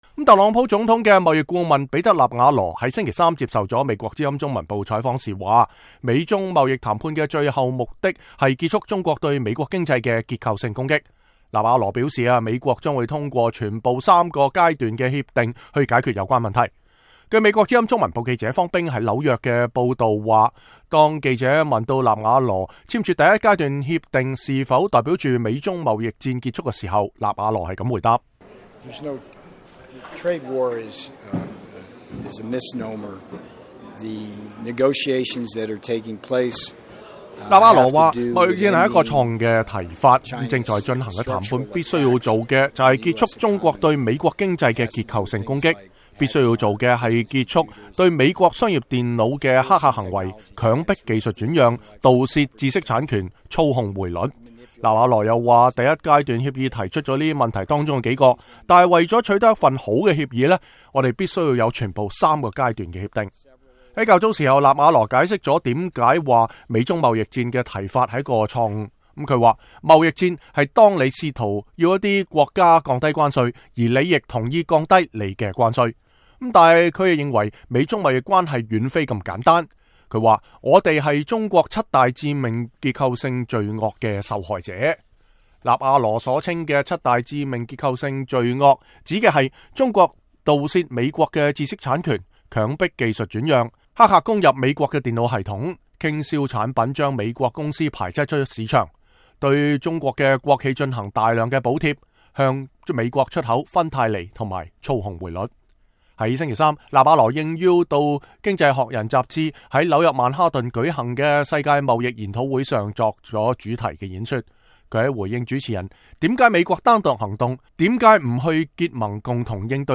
特朗普總統的貿易顧問彼得·納瓦羅星期三接受美國之音採訪時指出，美中貿易談判的最終目的是結束中國對美國經濟的結構性攻擊。他表示，美國將通過全部三個階段的協議來解決這些問題。